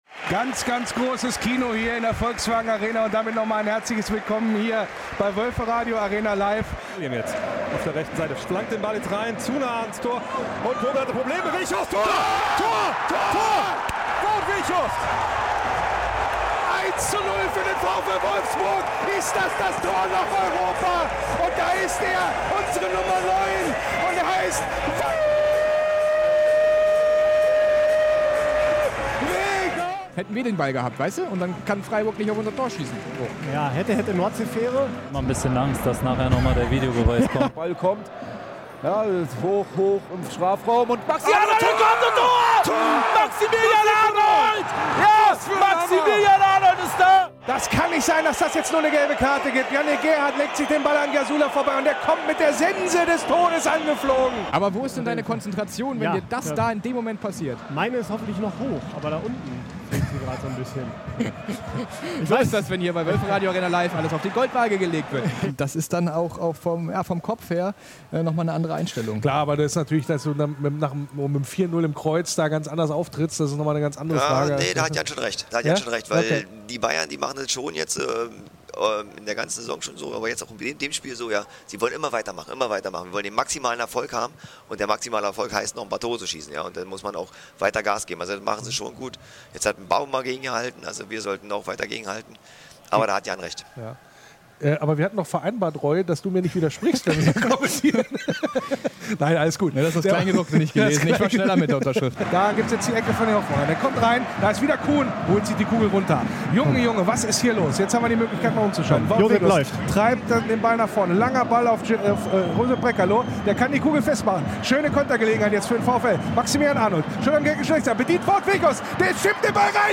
• Die Blindenreportage ist  in den Blöcken A bis F auf allen Rängen zu empfangen.